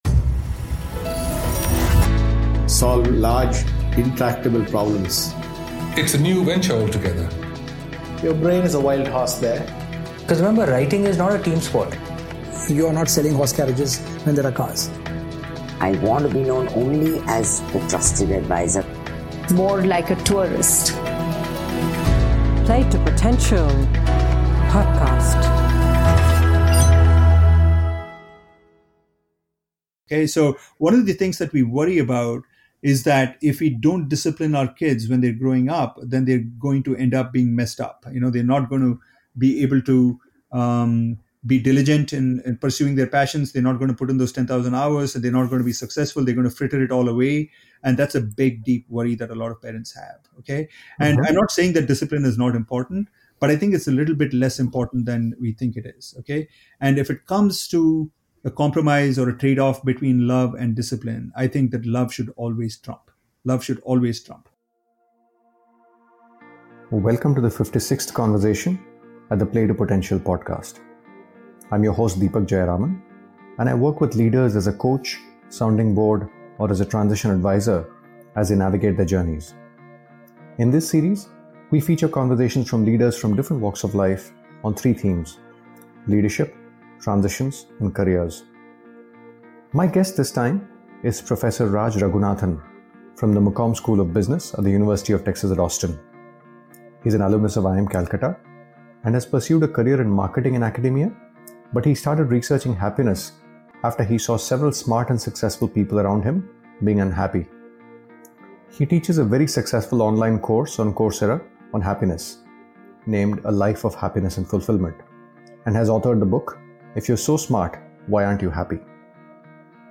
ABOUT THE PODCAST Play to Potential podcast started in Dec 2016 and features conversations around three broad themes - Leadership, Transitions and Careers.